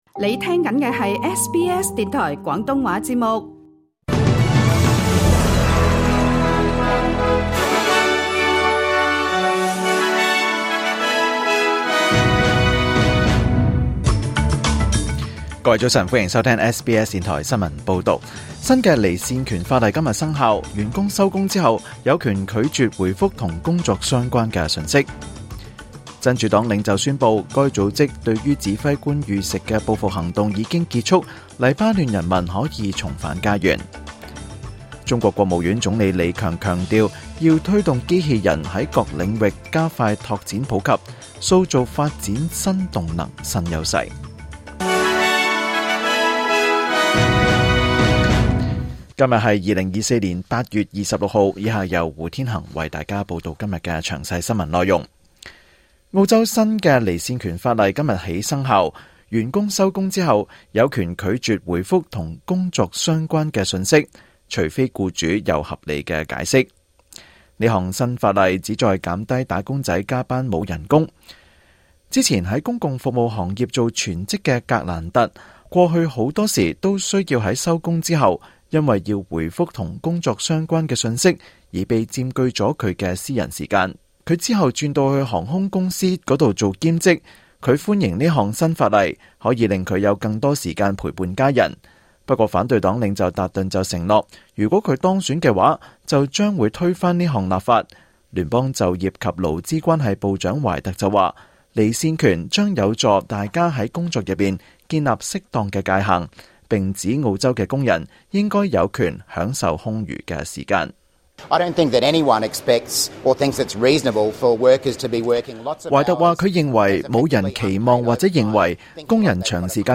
2024年8月26日SBS廣東話節目詳盡早晨新聞報道。